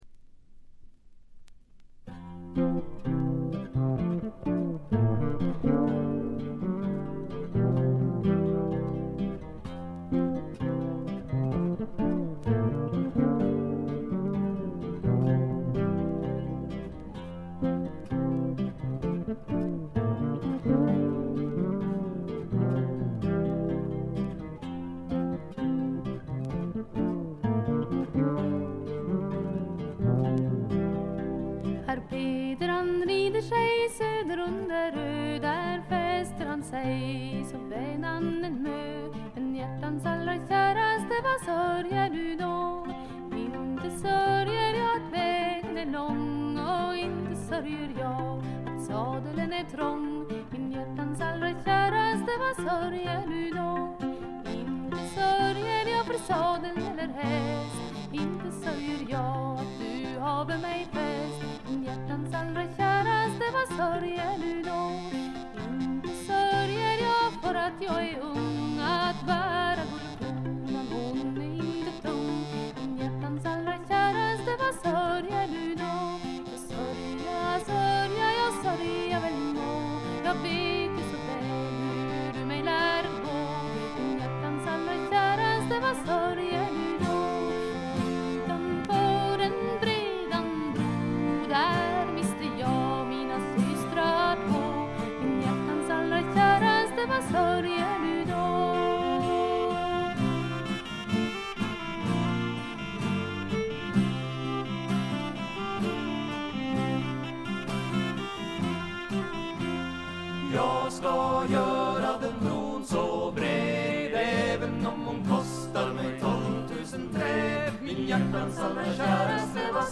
軽微なチリプチ少し。
スウェーデンのトラッド・フォーク・グループ。
試聴曲は現品からの取り込み音源です。
Recorded At - Metronome Studio, Stockholm